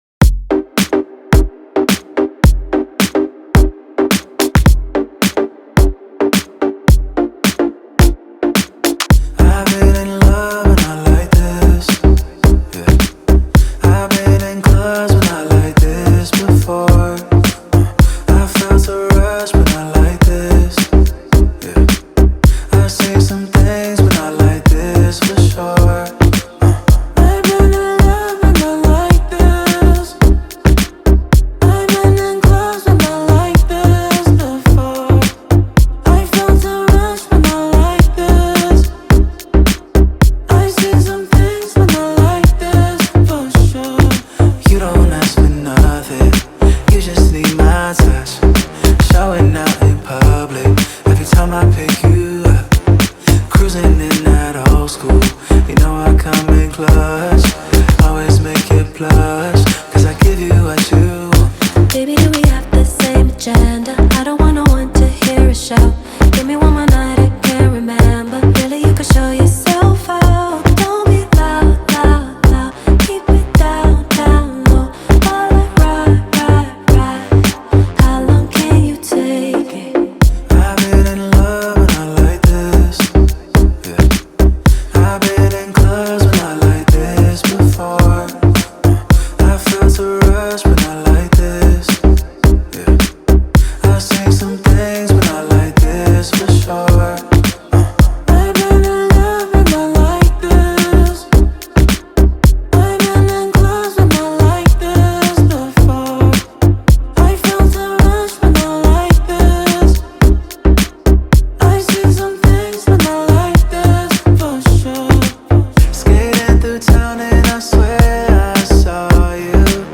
Hip Hop
Singer and songwriter